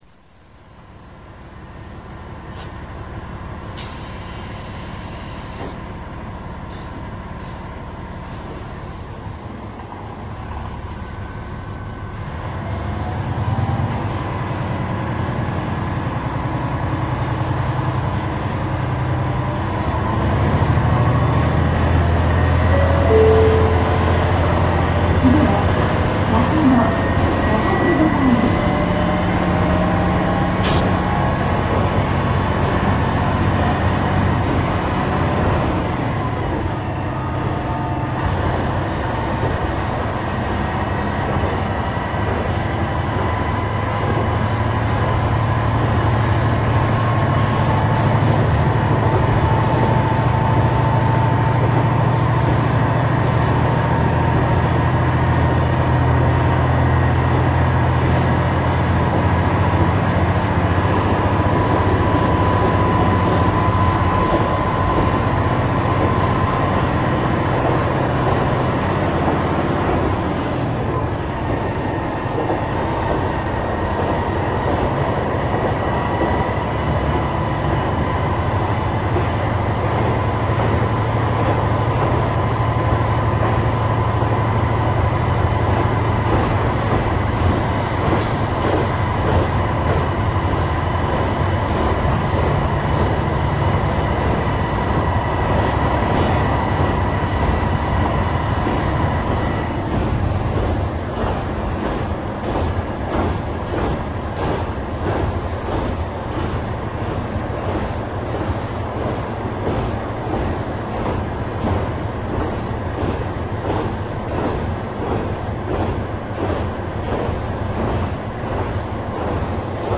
走行音[mt30a.ra/330KB]
駆動機関：PE6HT03A(250PS)×1